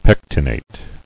[pec·ti·nate]